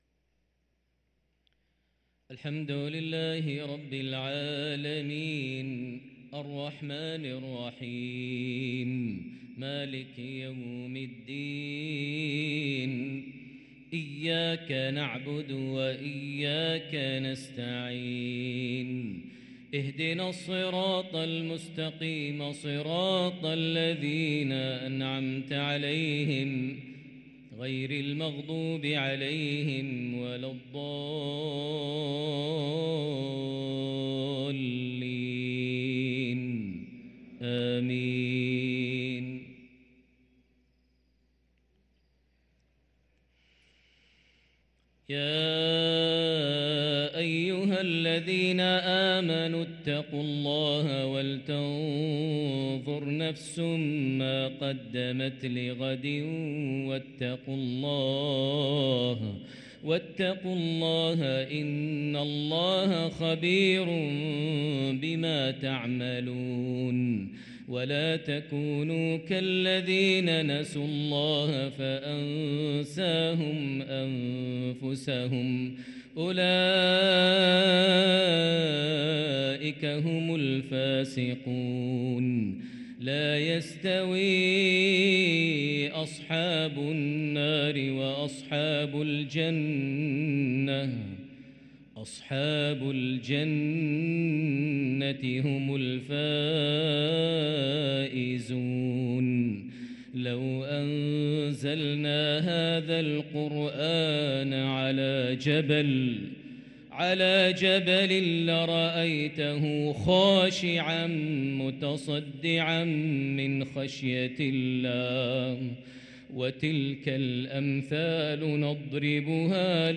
صلاة المغرب للقارئ ماهر المعيقلي 25 جمادي الآخر 1444 هـ
تِلَاوَات الْحَرَمَيْن .